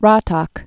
(rätäk)